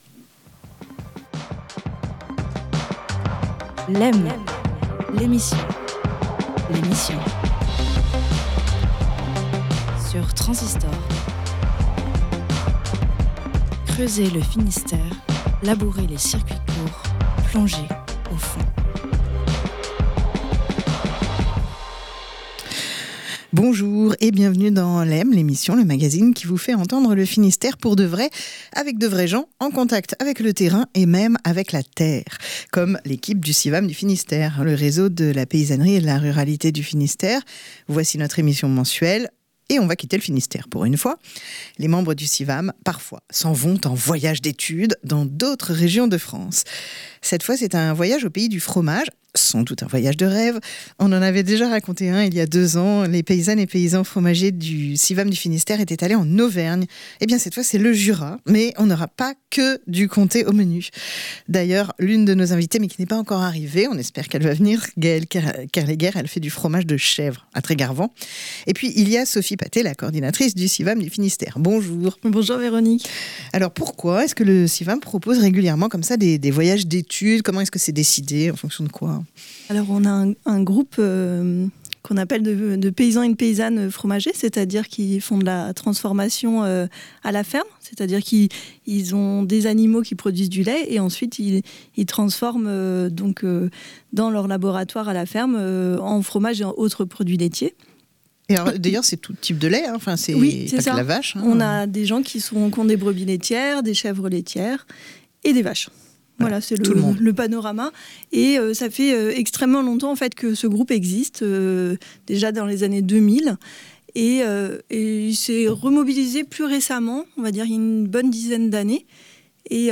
Une émission mensuelle de Lem, la quotidienne, réalisée en partenariat avec le réseau Civam du Finistère